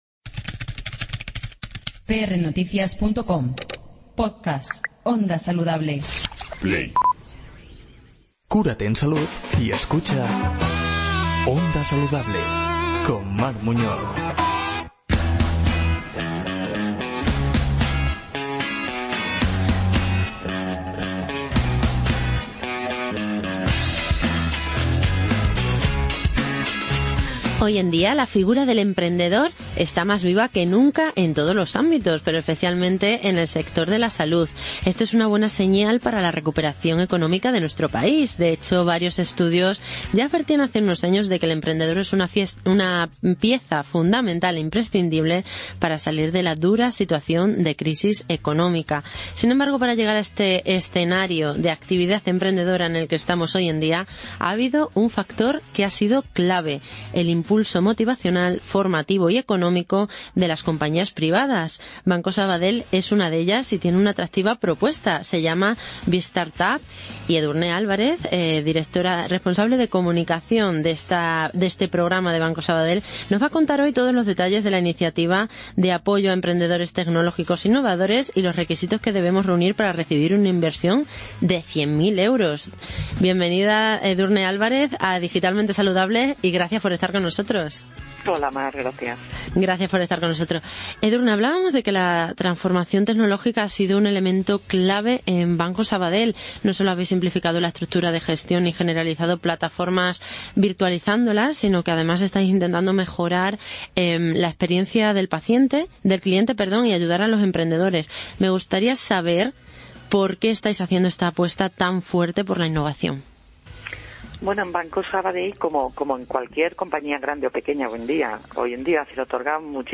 En una entrevista emitida en el programa Digitalmente Saludable con la colaboración de Sanofi